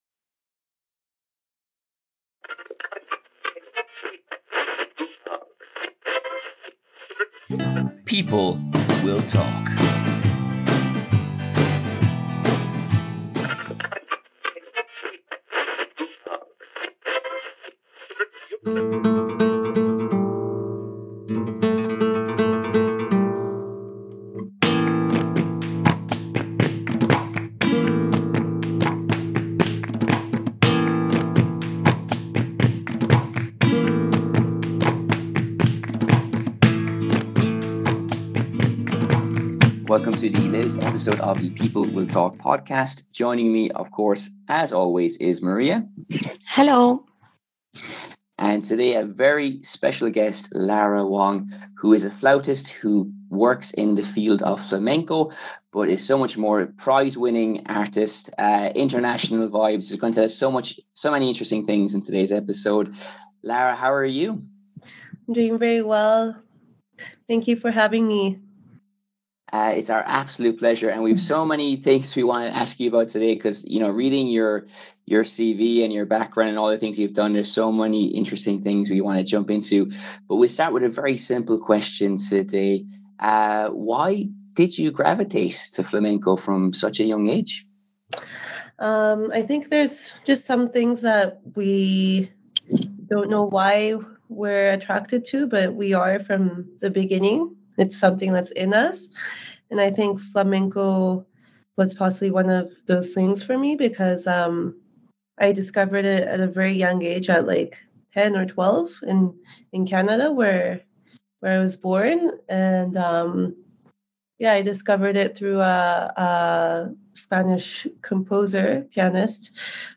The World in Music: An Interview